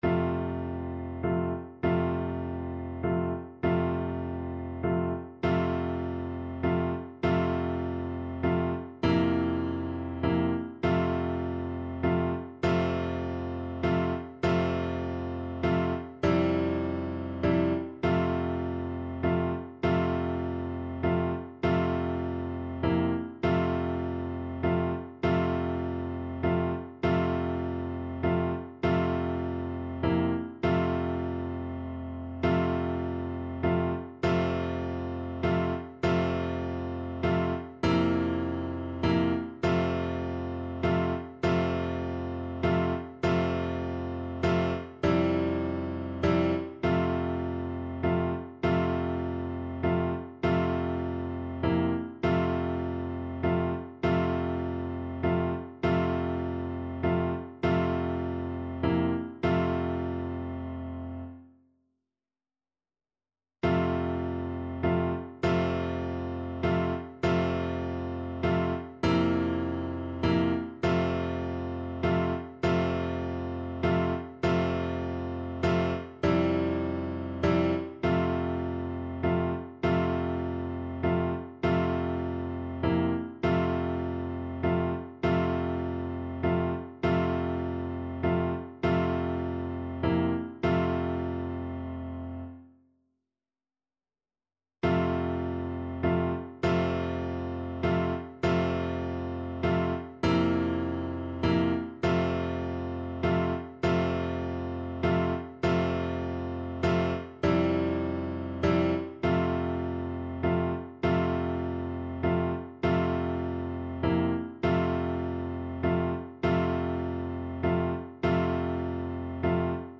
kolęda: Jam jest dudka (na klarnet i fortepian)
Symulacja akompaniamentu